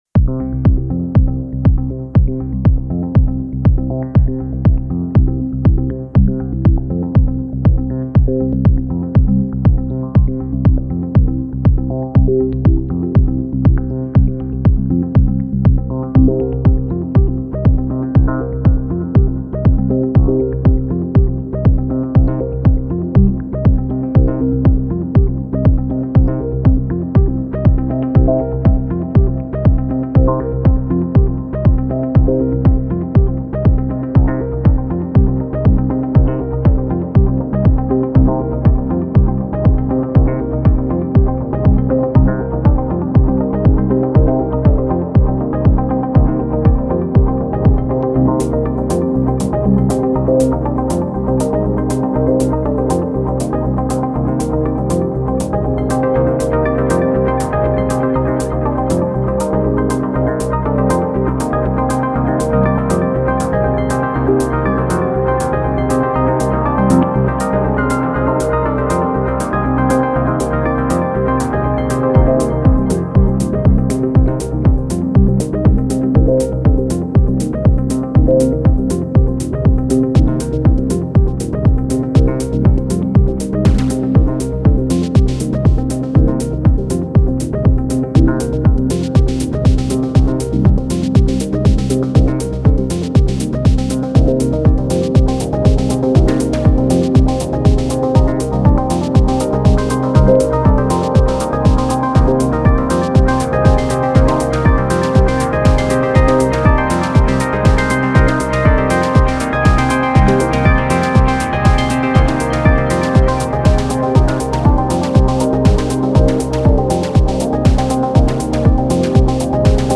First Jam with Take 5 and elektron boxes (A4, DN).
Sequences from the OT arp
Trying to get better at 4 on the floor music :upside_down_face: